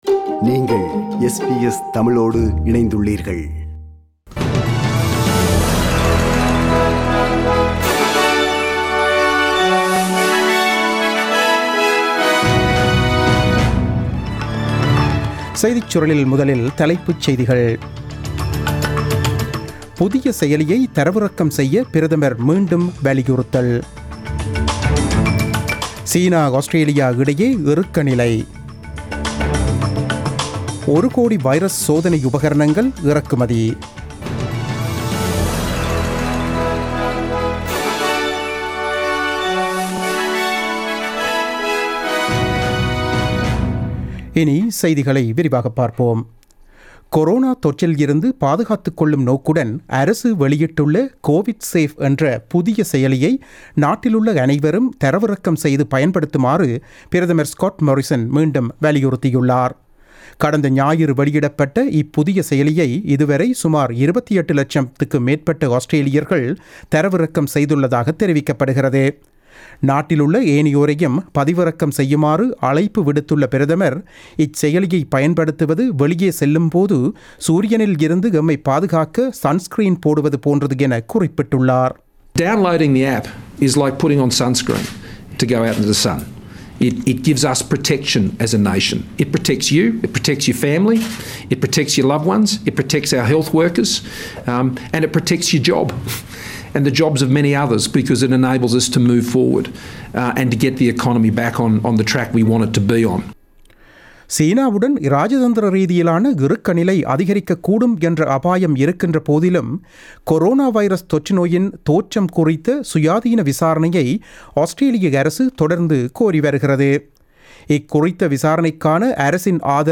The news bulletin broadcasted on 29 April 2020 at 8pm.